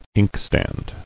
[ink·stand]